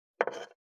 592魚切る,肉切りナイフ,まな板の上,包丁,
効果音厨房/台所/レストラン/kitchen食器食材